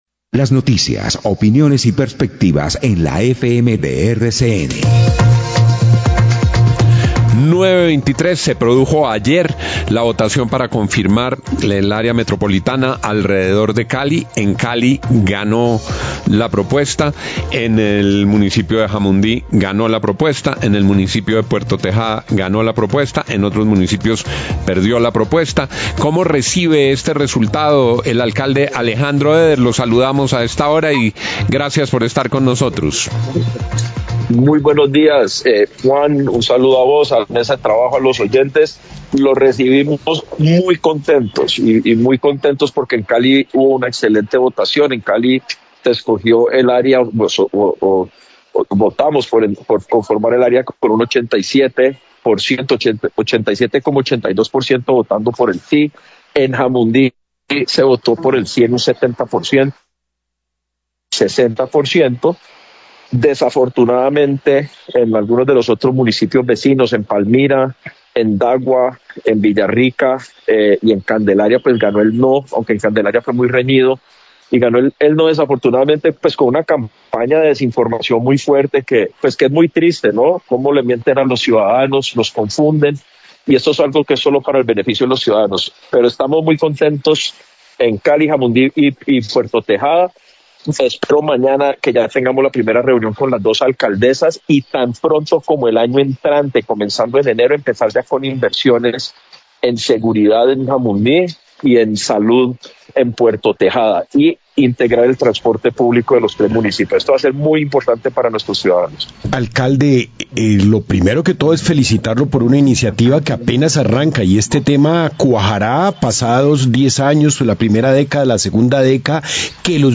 Radio
entrevistas